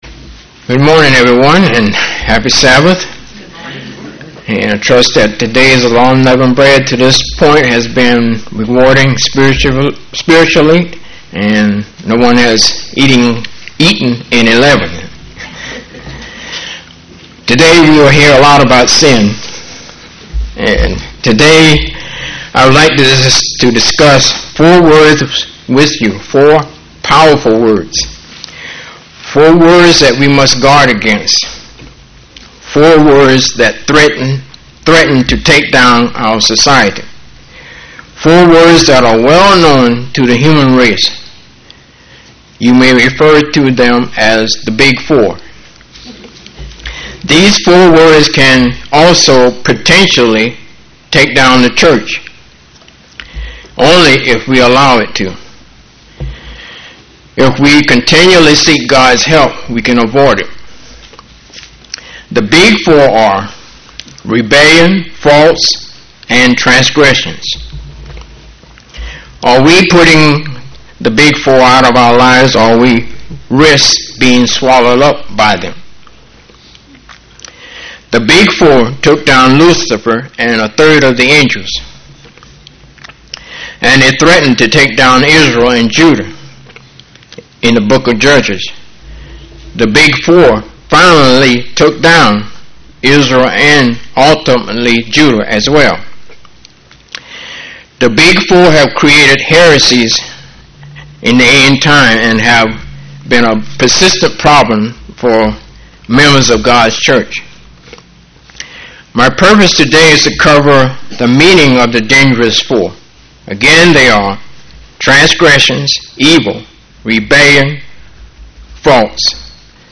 UCG Sermon Studying the bible?
Given in St. Petersburg, FL